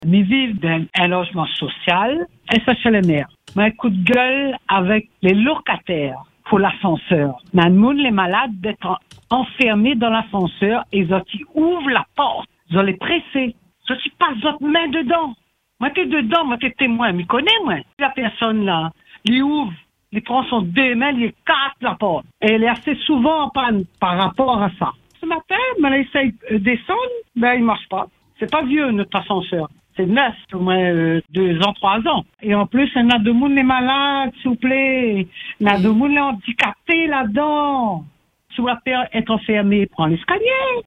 Une résidente témoigne et pointe du doigt le comportement de certains locataires.